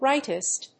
音節right･ist発音記号・読み方ráɪtɪst
• / ‐ṭɪst(米国英語)